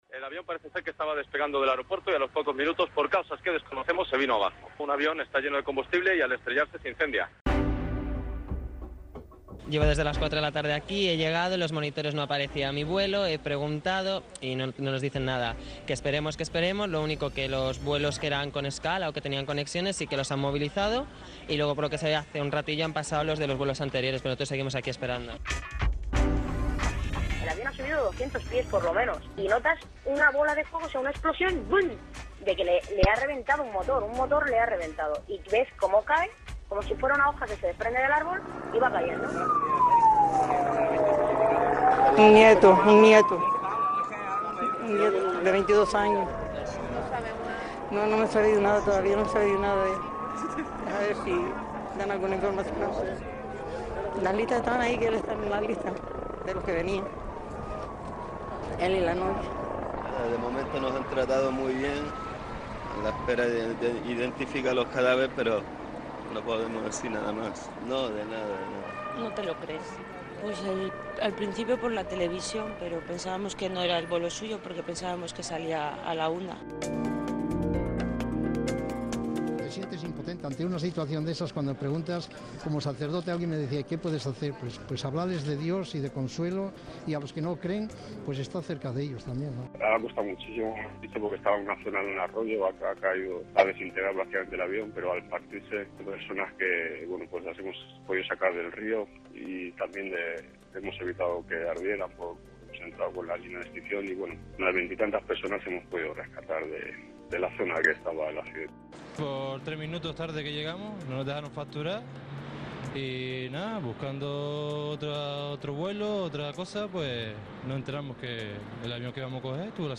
IB3 Ràdio ha recuperat les veus de la tragèdia.